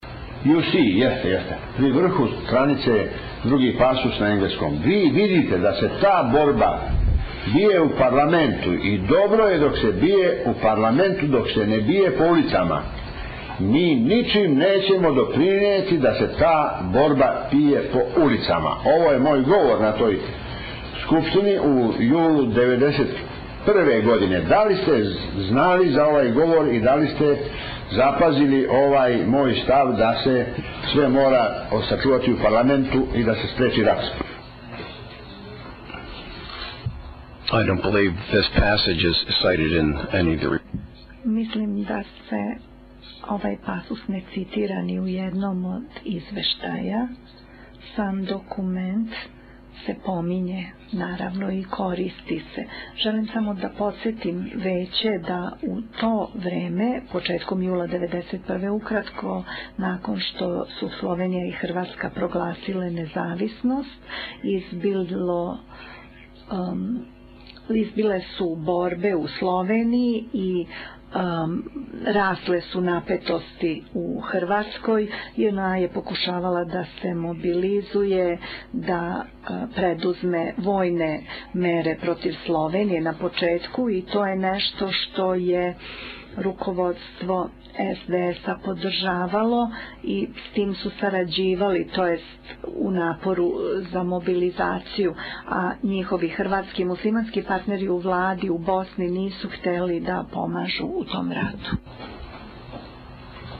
Karadžić čita svoj govor u Parlamentu BiH koji navodno pokazuje njegove miroljubive namjere